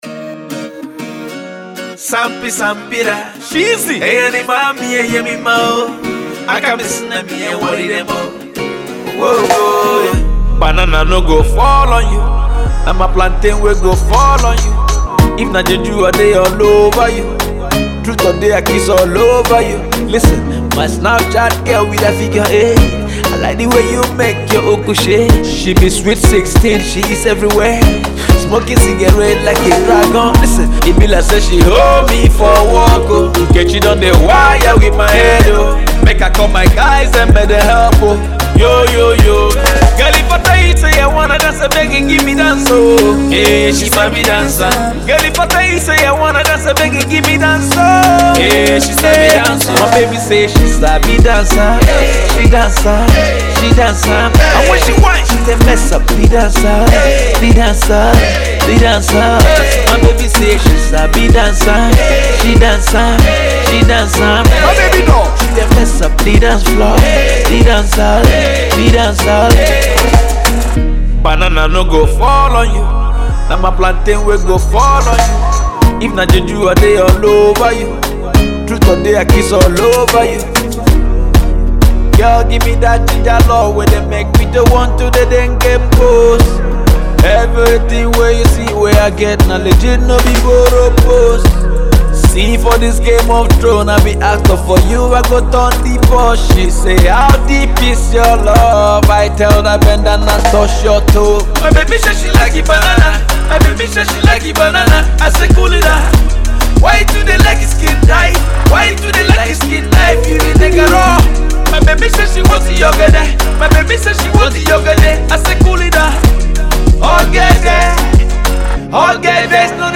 up-tempo